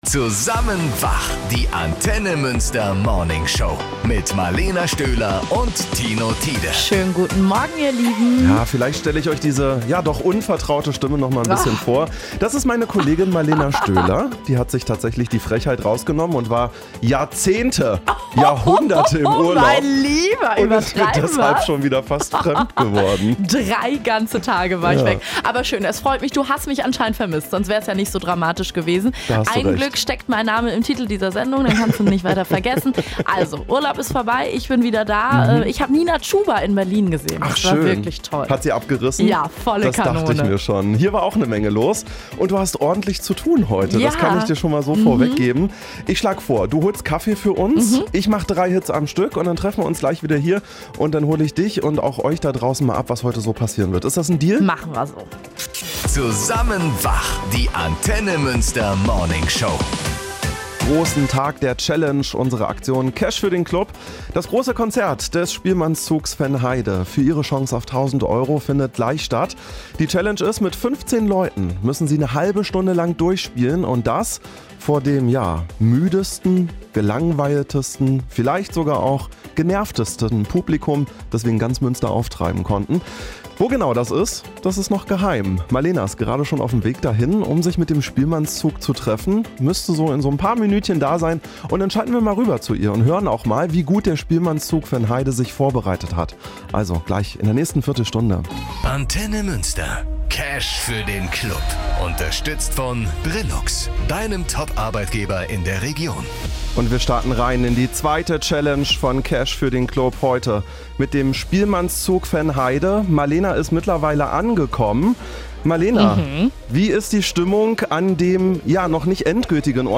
30 Minuten am Stück musste der Spielmannszug Vennheide die Autofahrer:innen im Stau auf der Warendorfer Straße bei Handorf entertainen.
Letztendlich waren es sogar 28 Musiker:innen vom Spielmannszug Vennheide, die in Handorf erschienen sind, um die Challenge zu erfüllen.
Zahlreiche Autofahrer:innen hupten zur Begrüßung und zeigten sich freudig überrascht wegen der unverhofften Erheiterung auf ihrem Arbeitsweg.
cfdc-spielmannszug_zusammenschnitt.mp3